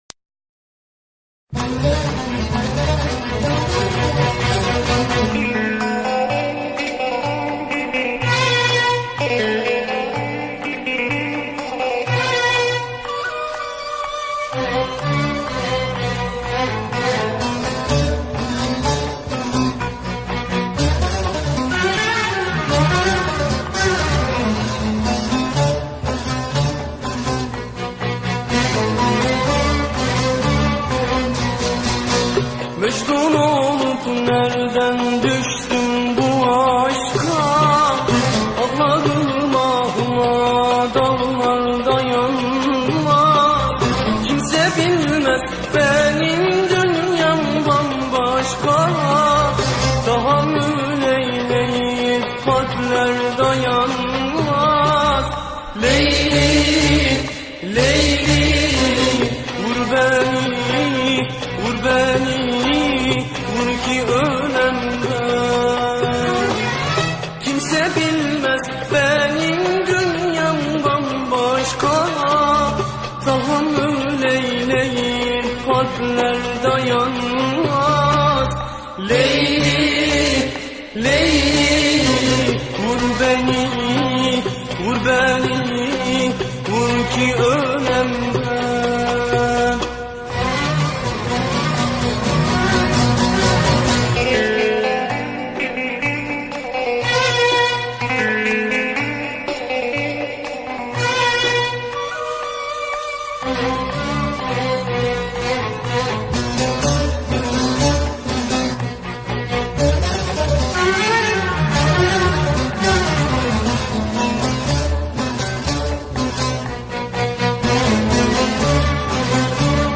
ژانر: پاپ ترکی